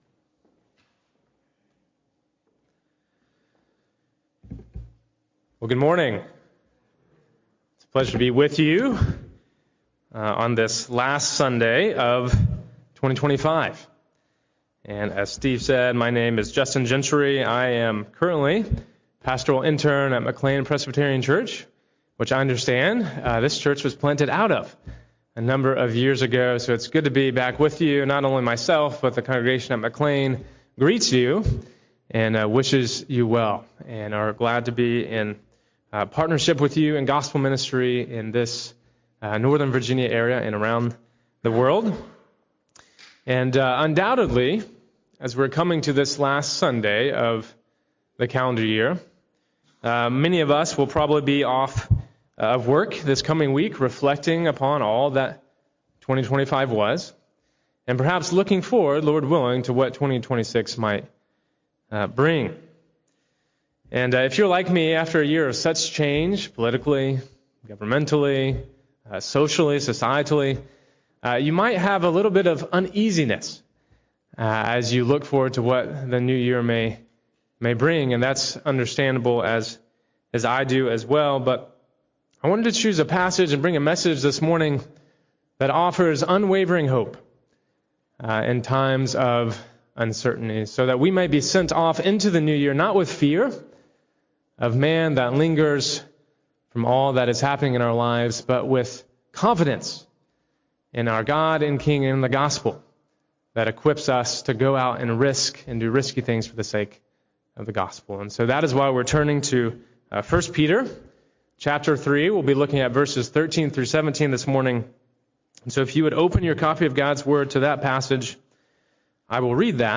Living with Gospel Hopefulness: Sermon on 1Peter 3:13-17 - New Hope Presbyterian Church